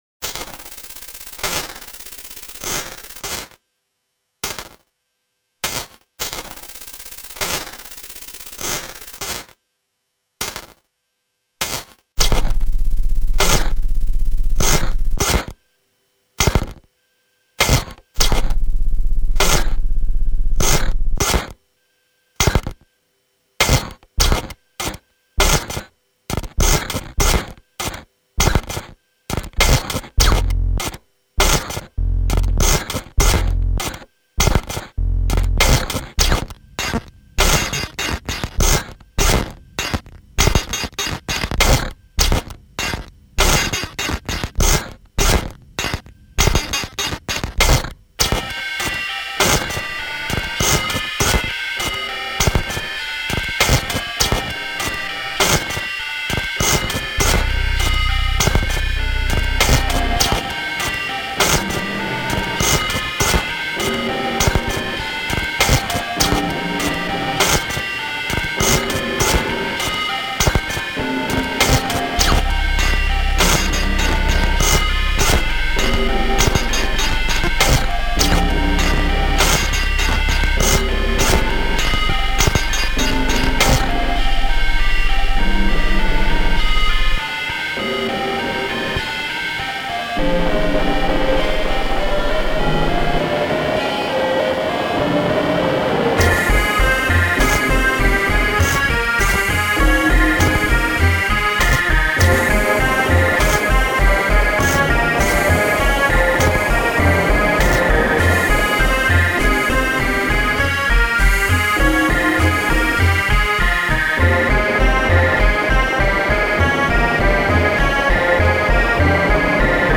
wandering electronic music